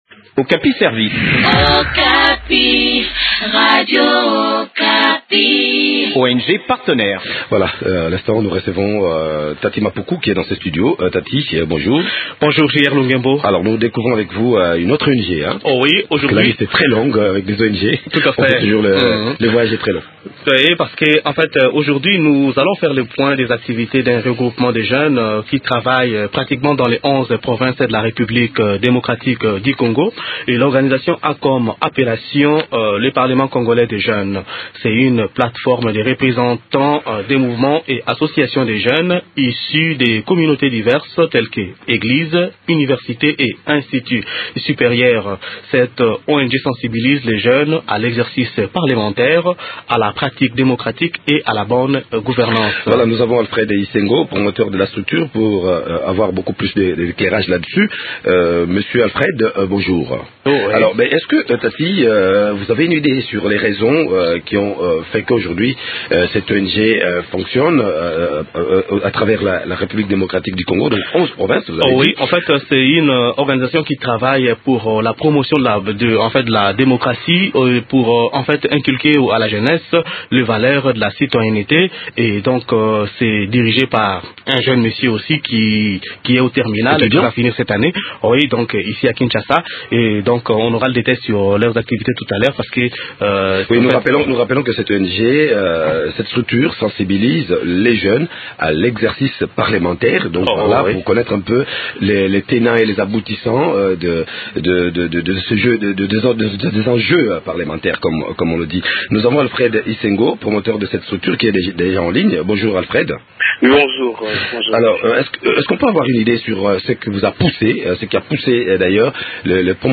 Découvrons les activités de cette plate forme dans cet entretien